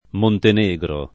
vai all'elenco alfabetico delle voci ingrandisci il carattere 100% rimpicciolisci il carattere stampa invia tramite posta elettronica codividi su Facebook Montenegro [ monten %g ro ] top. m. — stato balcanico — anche cognome